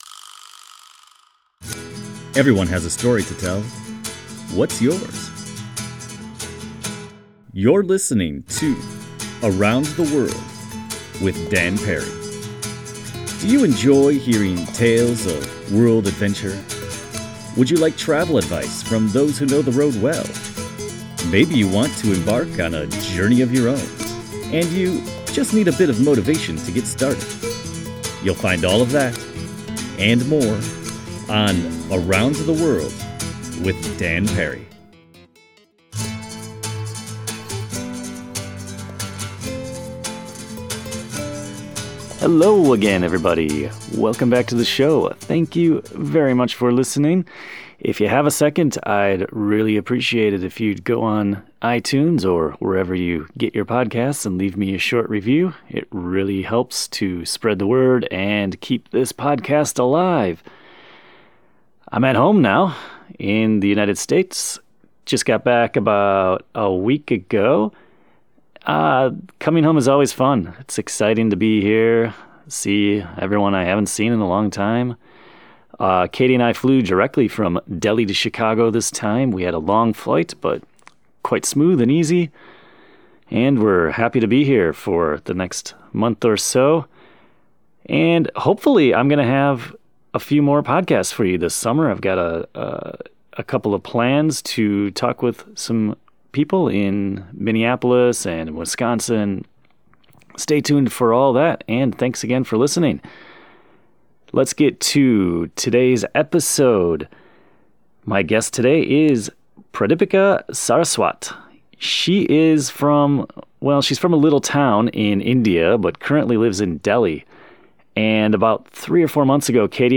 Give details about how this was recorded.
Talking Kashmir over a wonderful dinner.